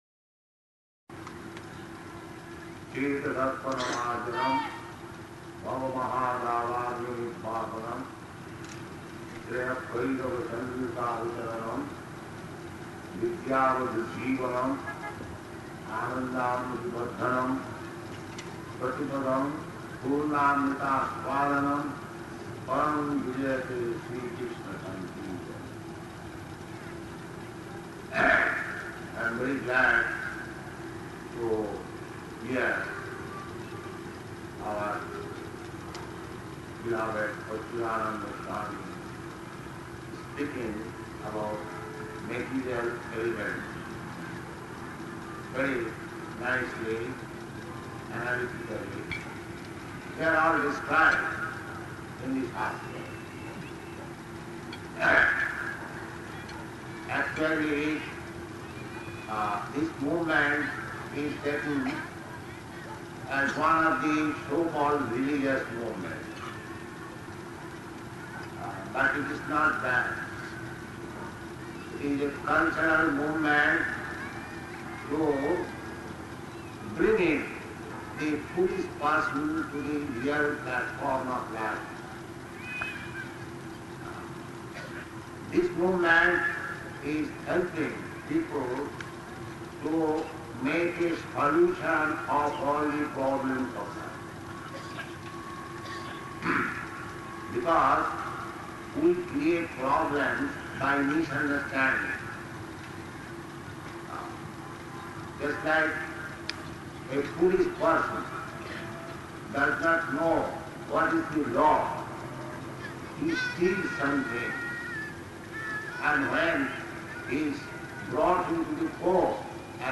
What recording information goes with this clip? Location: Delhi